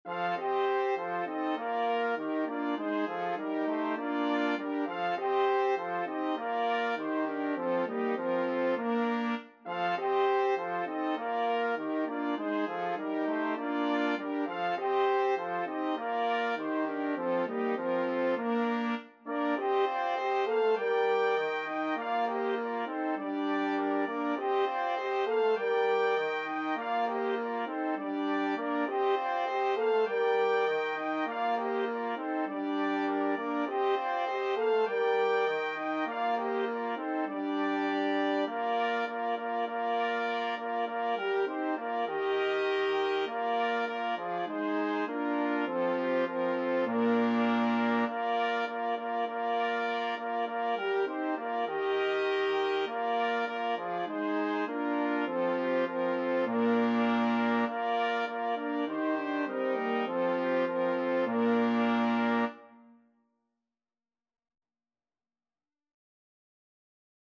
Free Sheet music for Brass Quartet
Trumpet 1Trumpet 2French HornTrombone
4/4 (View more 4/4 Music)
Bb major (Sounding Pitch) (View more Bb major Music for Brass Quartet )
Classical (View more Classical Brass Quartet Music)